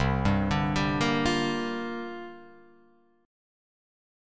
C7sus2sus4 chord